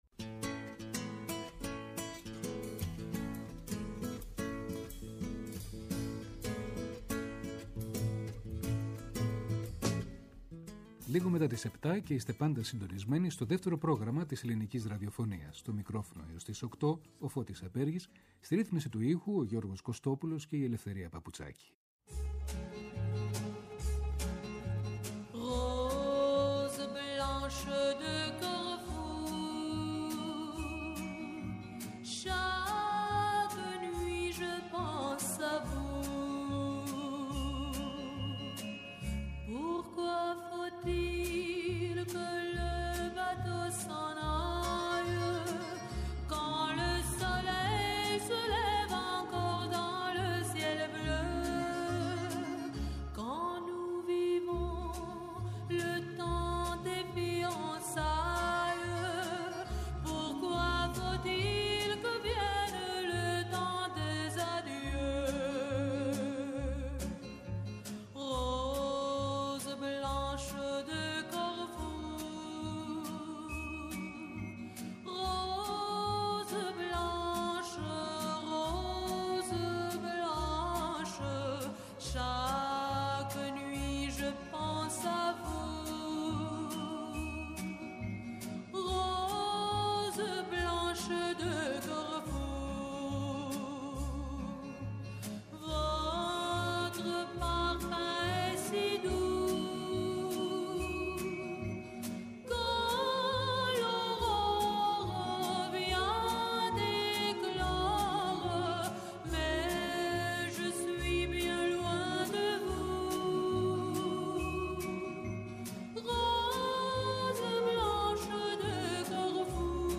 Με σπάνια και ανέκδοτα τραγούδια της
ΔΕΥΤΕΡΟ ΠΡΟΓΡΑΜΜΑ Αφιερώματα Μουσική Συνεντεύξεις